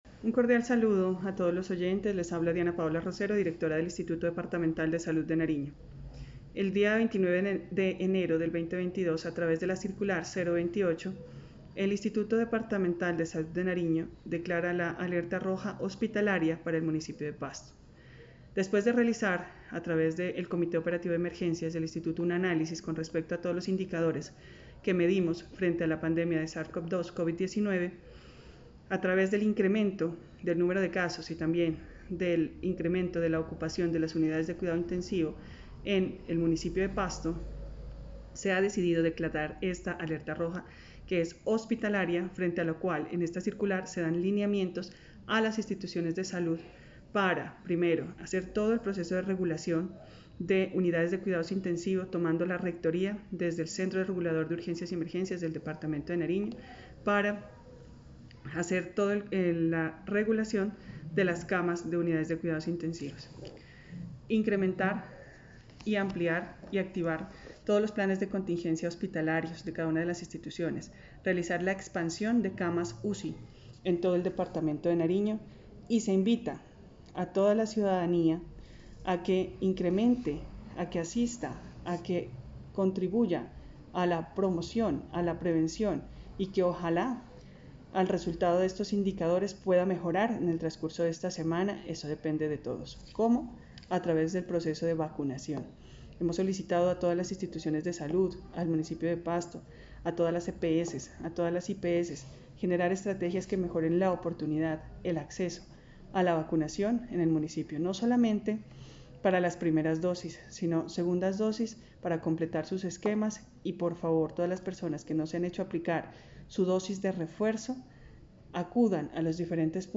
Declaración de la directora del IDSN Diana Paola Rosero.